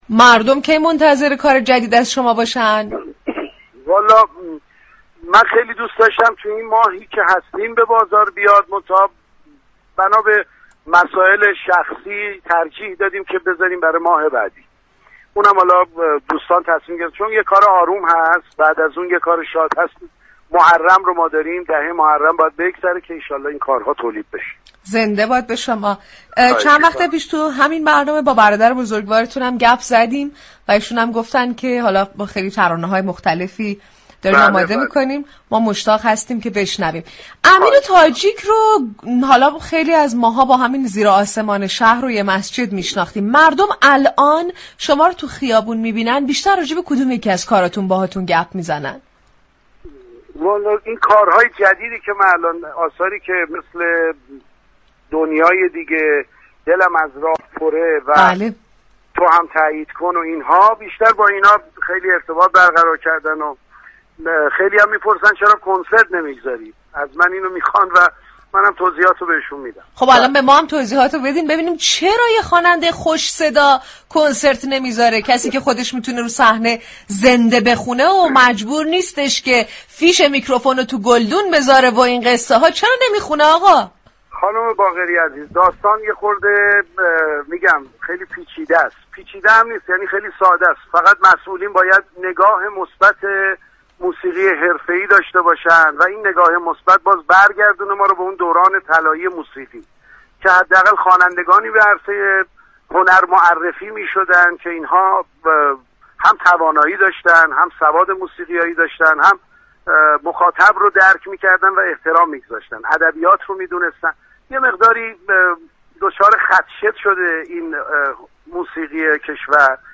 امیر تاجیك در گفتگو با رادیو صبا خطاب به مسئولین از مشكلات موسیقی كشور گفت